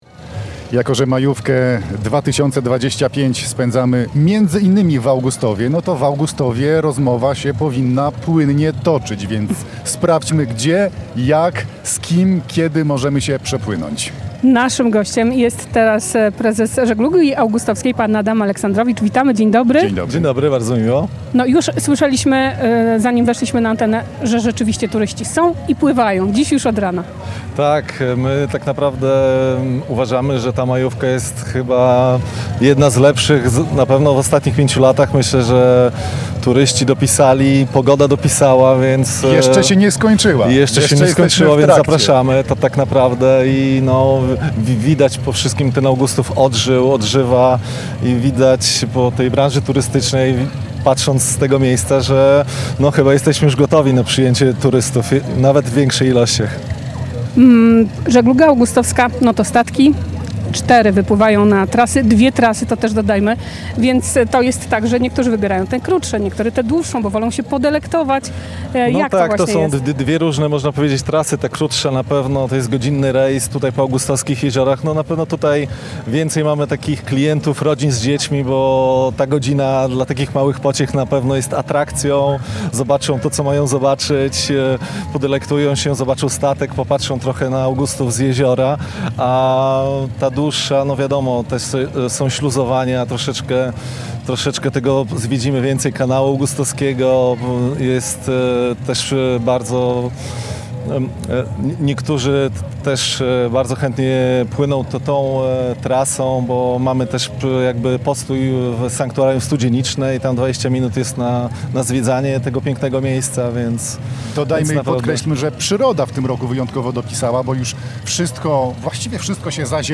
W piątek (2.05) Polskie Radio Białystok nadawało na żywo z serca letniej stolicy Polski, czyli Augustowa. Plenerowe studio trwało od 14:00 do 18:30 na Błoniach nad Nettą, a antenowy czas wypełnialiśmy ciekawymi konkursami i rozmowami z zaproszonymi gośćmi.
Rozmowa z Bodanem Dyjukiem , Członkiem Zarządu Województwa Podlaskiego | Pobierz plik.